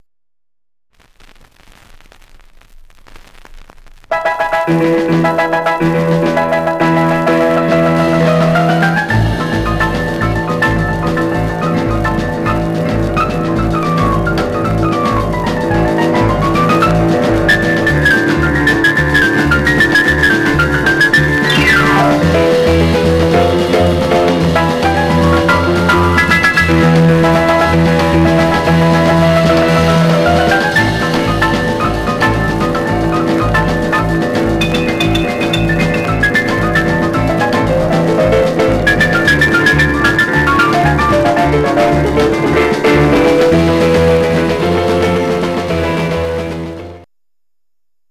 Surface noise/wear Stereo/mono Mono
R & R Instrumental